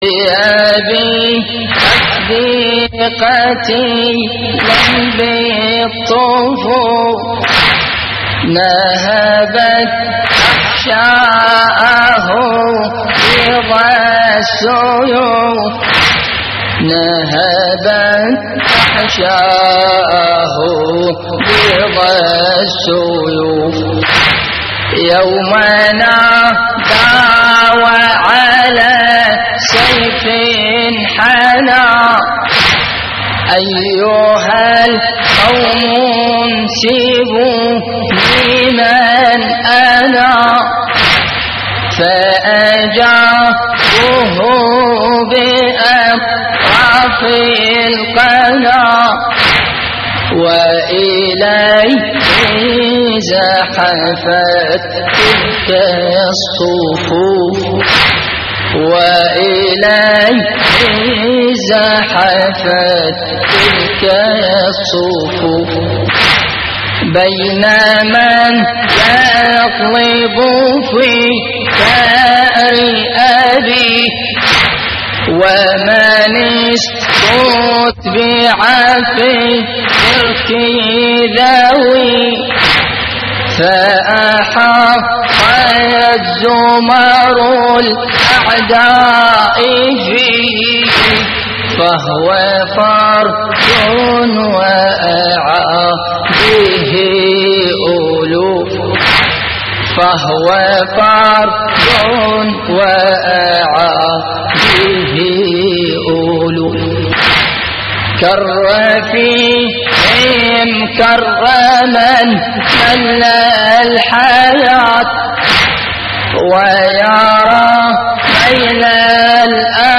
تحميل : بأبي أفدي قتيلاً في الطفوف / مجموعة من الرواديد / اللطميات الحسينية / موقع يا حسين
استديو